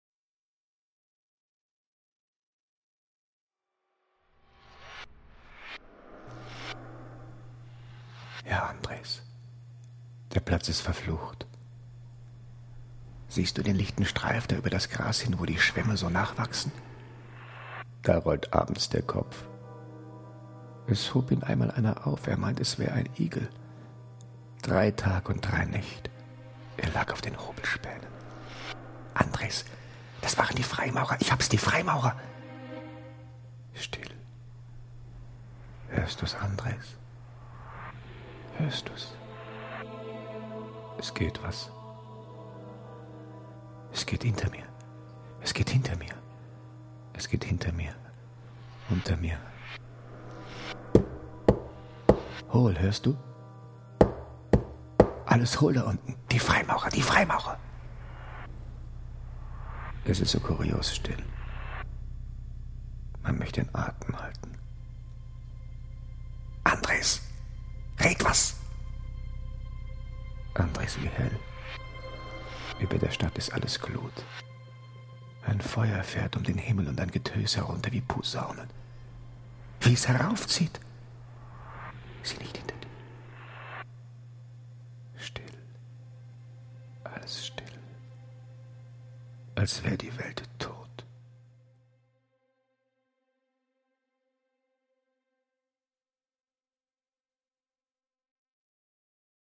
Theater